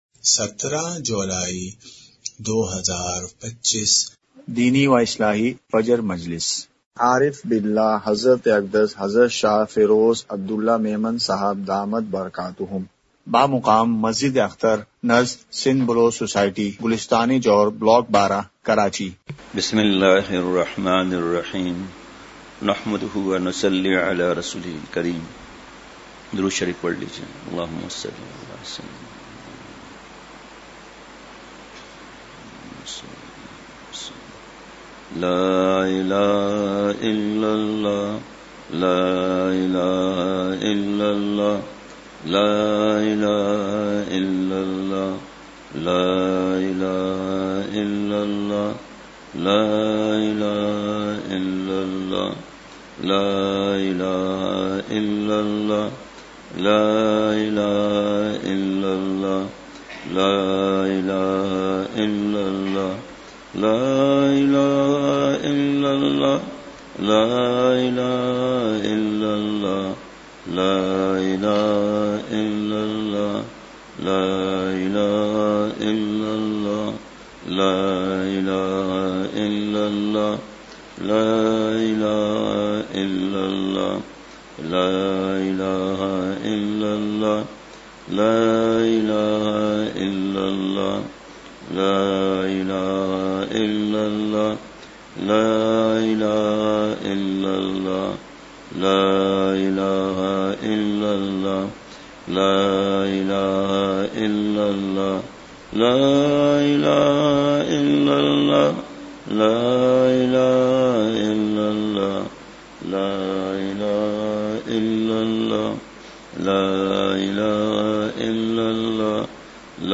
مقام:مسجد اختر نزد سندھ بلوچ سوسائٹی گلستانِ جوہر کراچی
مجلسِ ذکر:ذکر کلمہ طیّبہ!!